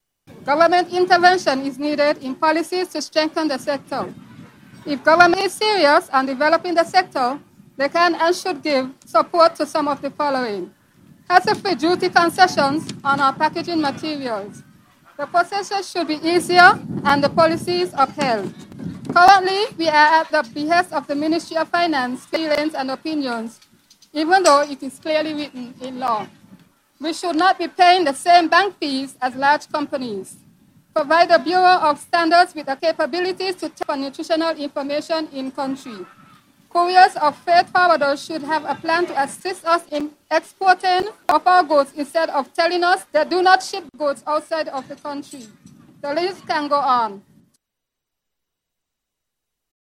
The St. Kitts Agro Processors Association is requesting that the government do more to develop the sector. A representative from the sector made a public statement at the St. Kitts. Agriculture Open Day event, organized by the Ministry of Agriculture Fisheries and Marine Resources.